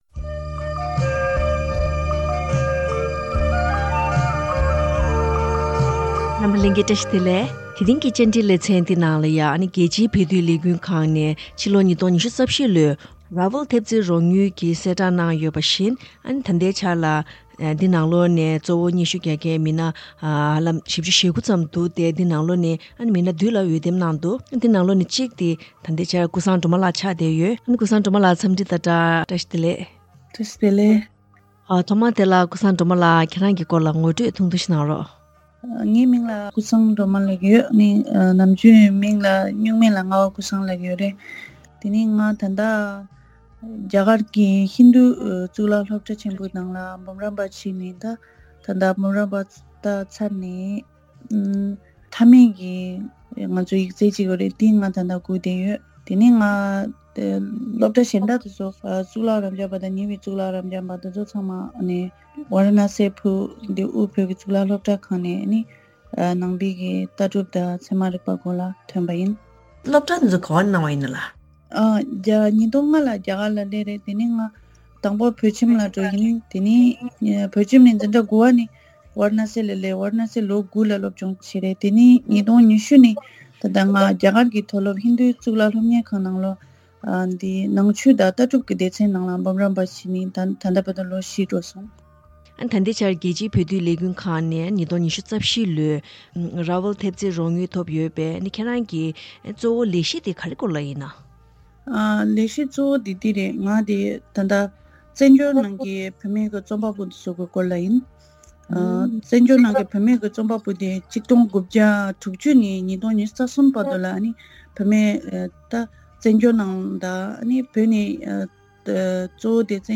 བཅར་འདྲིའི་ལེ་ཚན